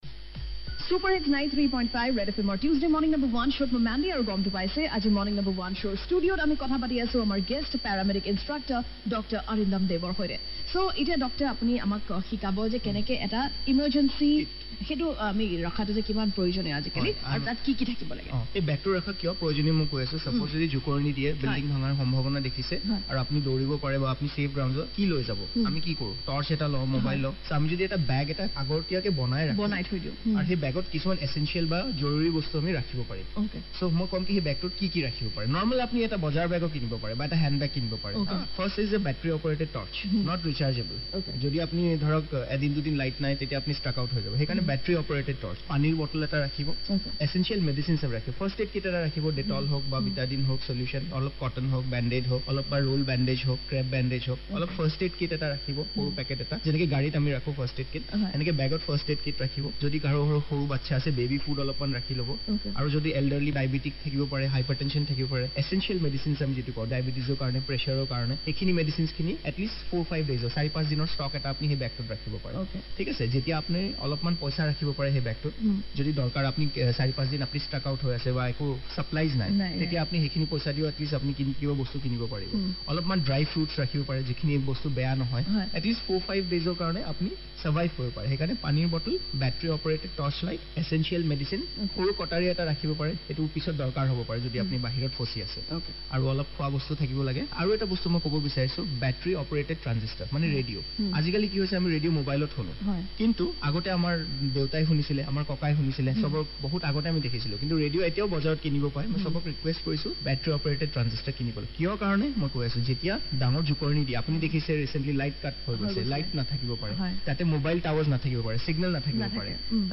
# Interview